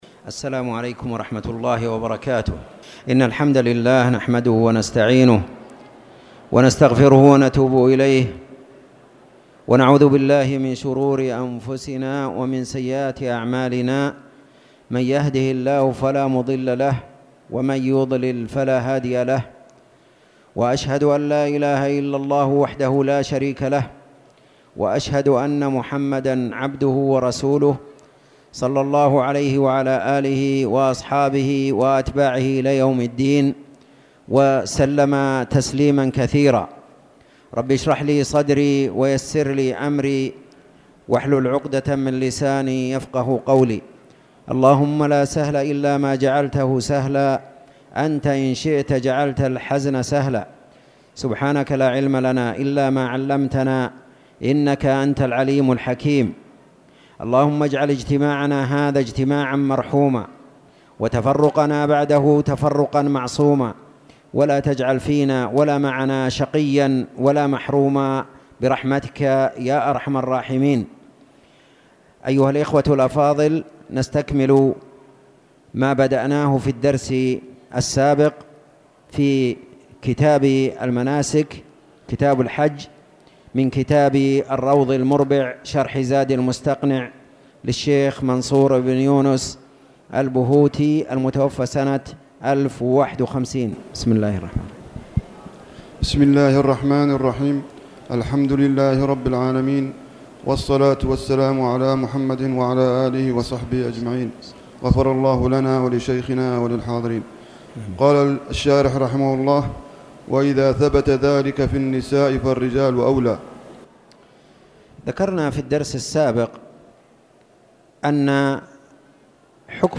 تاريخ النشر ١ جمادى الآخرة ١٤٣٨ هـ المكان: المسجد الحرام الشيخ